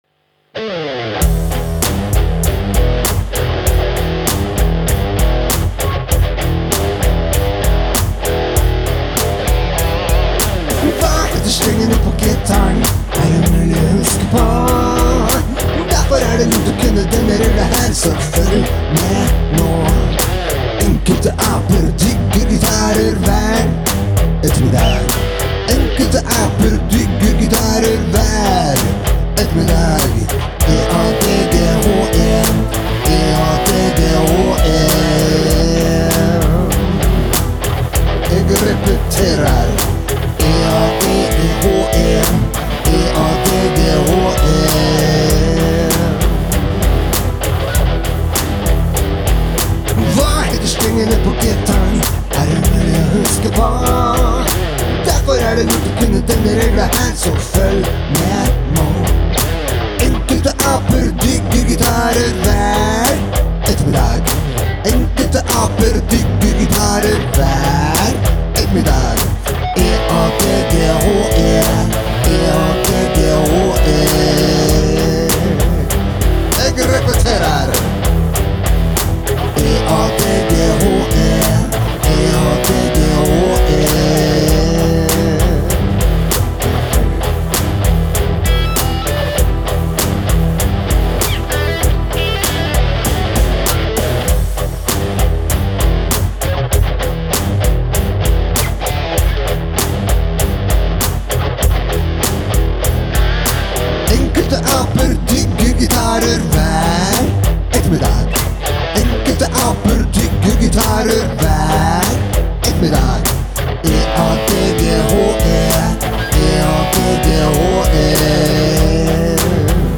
Mandag 24. januar 2022: HVA HETER STRENGENE PÅ GITAREN? SKOLESANG (Sang nr 145 – på 145 dager)
programmering, bass, piano og to gitarer